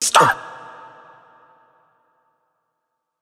TS Vox_10.wav